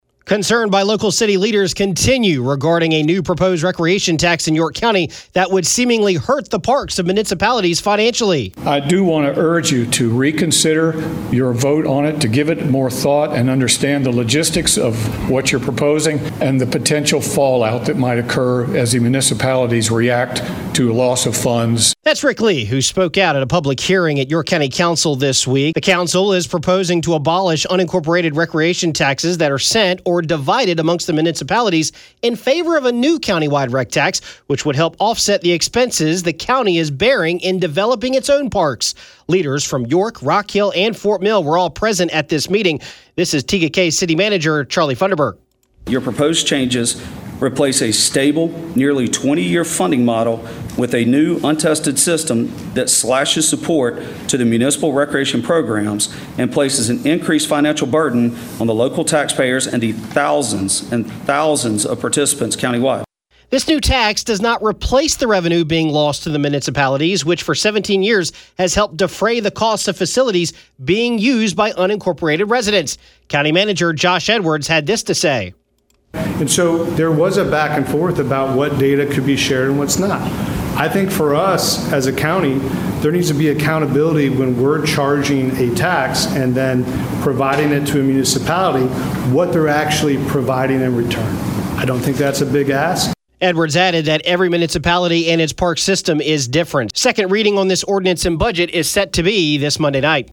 AUDIO: City leaders and residents speak out regarding the proposed recreation tax by York County